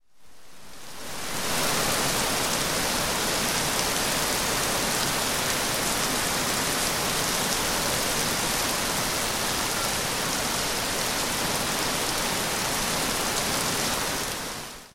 Дождь мокрые капли вода капает
dozhd_mokrie_kapli_voda_kapaet_clg.mp3